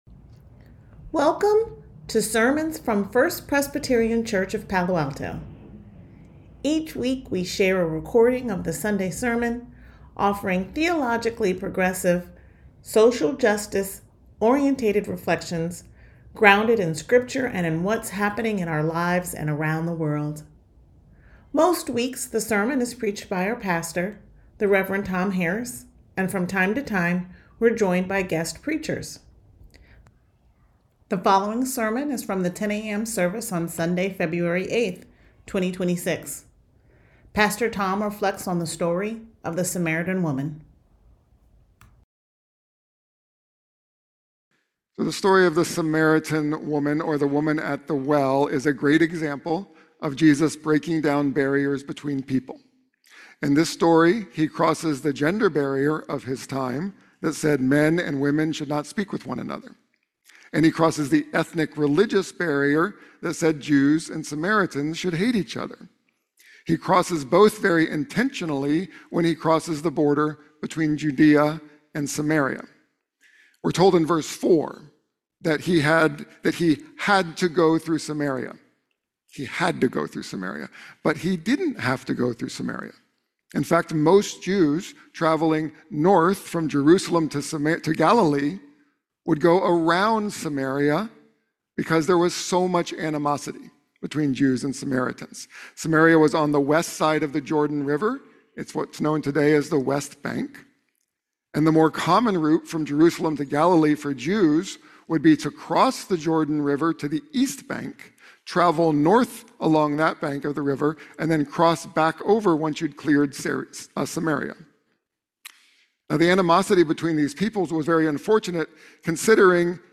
sermon2826a.mp3